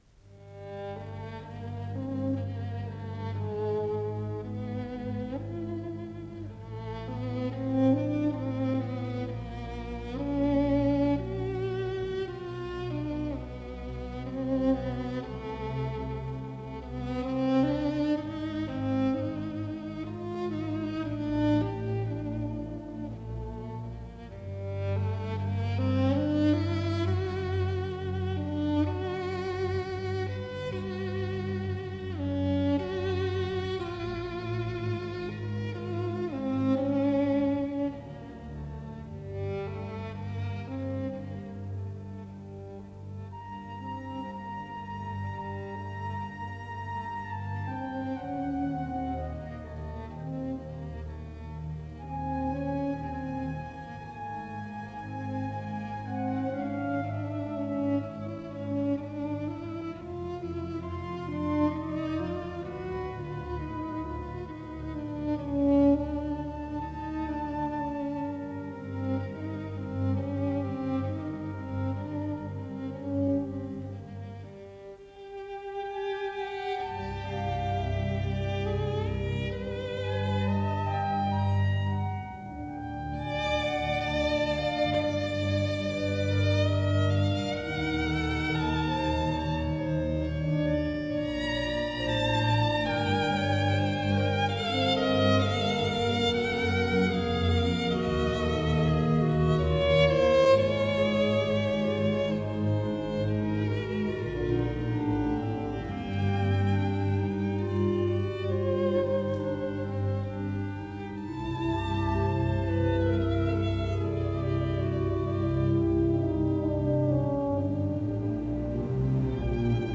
Исполнитель: Давид Ойстрах - скрипка
Концерт №2 для скрипки с оркестром
до диез минор